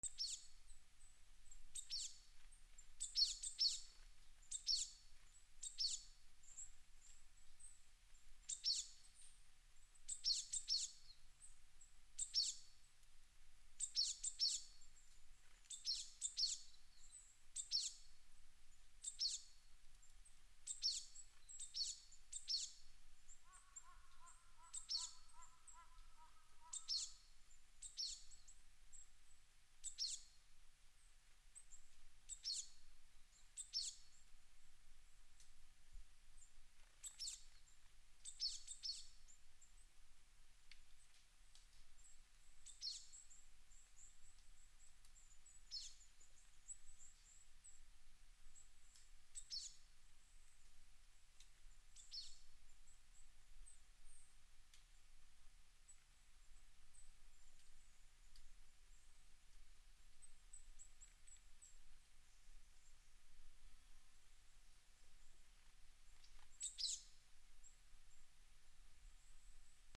/ B｜環境音(自然) / B-20 ｜森
山(森) 鳥 近め
NT4 高野山